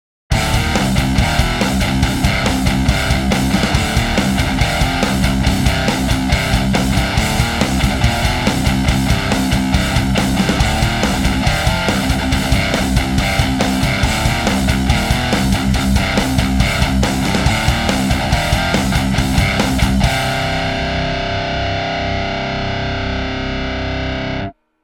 METAL Fortin 1.mp3